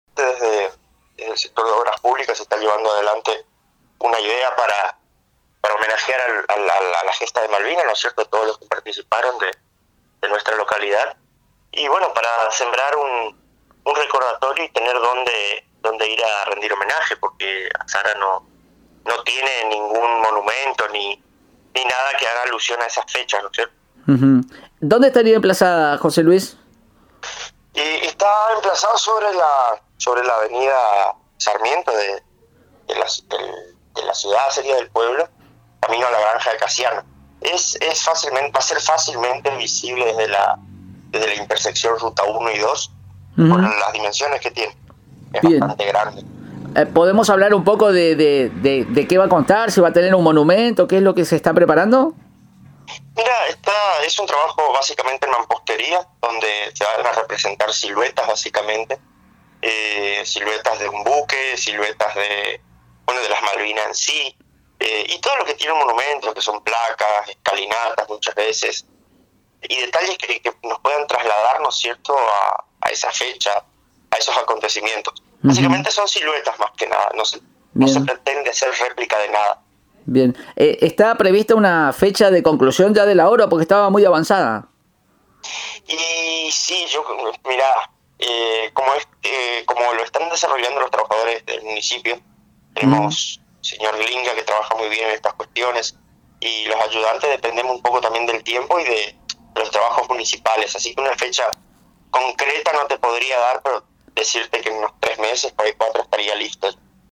En comunicación telefónica con el programa “Más Vale Tarde” declaró […]